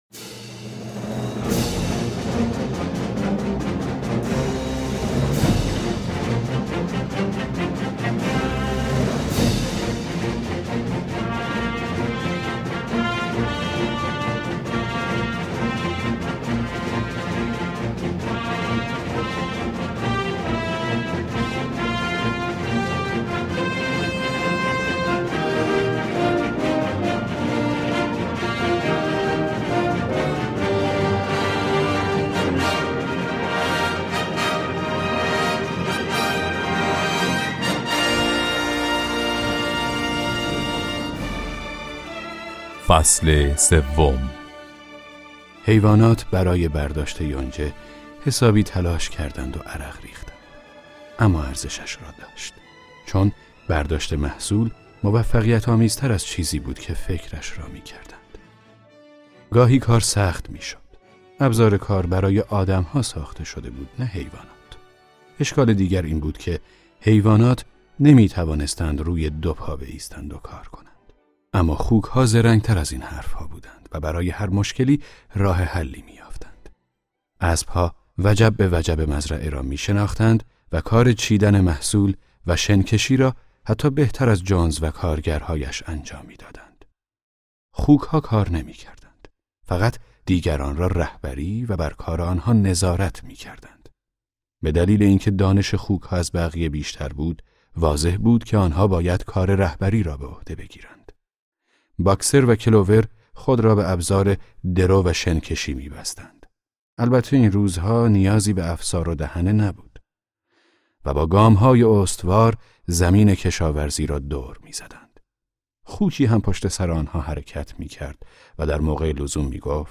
کتاب صوتی مزرعه حیوانات اثر جورج اورول { قسمت 3 }